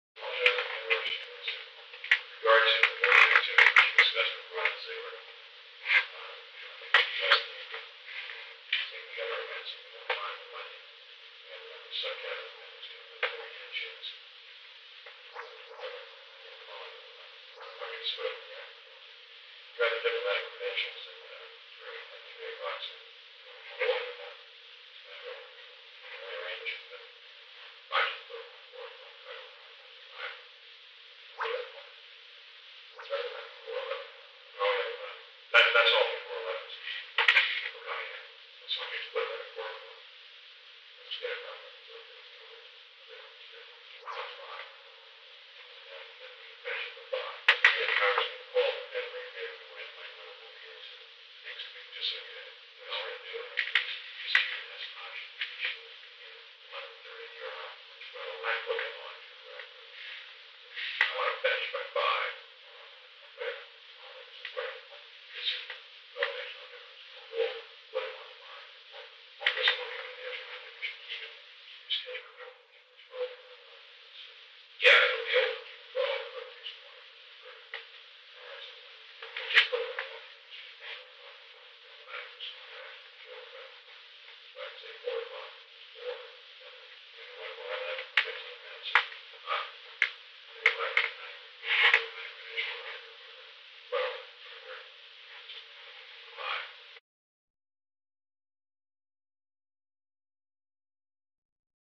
Secret White House Tapes
Conversation No. 416-9
Location: Executive Office Building